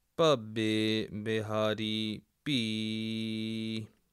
muharni - A Clojure library designed to hack up a web page for studying the Punjabi script and the pronunciation thereof approved for the reading of Sikh sacred texts.